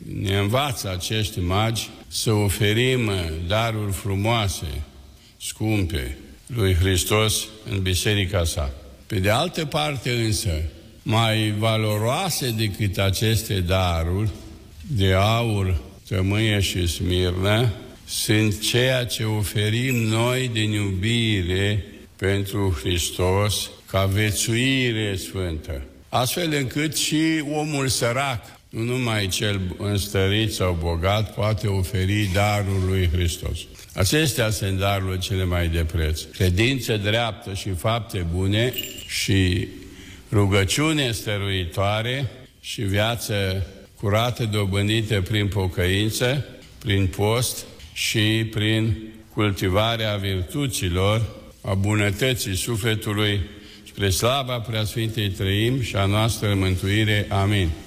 La Catedrala Patriarhală din București, slujba praznicului a fost oficiată de Preafericitul Părinte Daniel, Patriarhul Bisericii Ortodoxe Române. În cuvântul de învățătură rostit în fața miilor de credincioși, Patriarhul a vorbit despre semnificația darurilor aduse Pruncului Iisus de către magii de la Răsărit și despre sensul profund al dăruirii creștine.